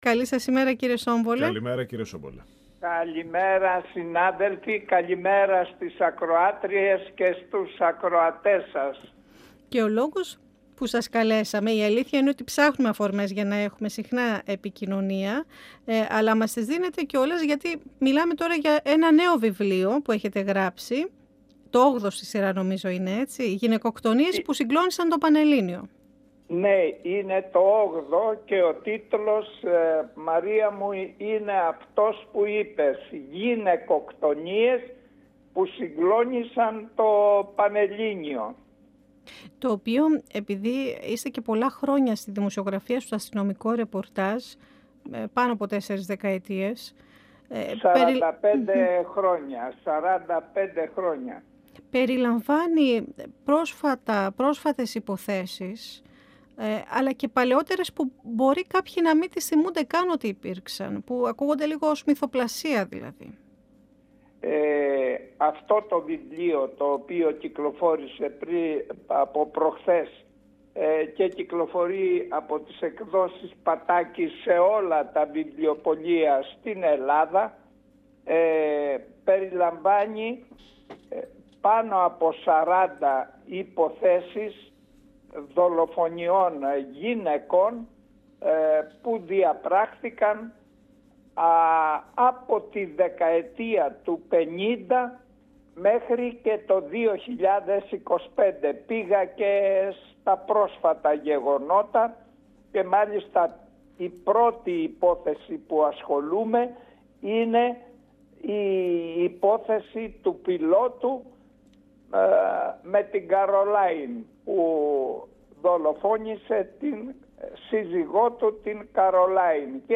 Στον 102 fm μίλησε ο γνωστός δημοσιογράφος, Πάνος Σόμπολος, για το νέο του βιβλίο για τις γυναικοκτονίες που συγκλόνισαν το πανελλήνιο.
Στον 102 fm μίλησε ο γνωστός δημοσιογράφος, Πάνος Σόμπολος, για το νέο του βιβλίο για τις γυναικοκτονίες που συγκλόνισαν το πανελλήνιο. 102FM Ο Μεν και η Δε Συνεντεύξεις ΕΡΤ3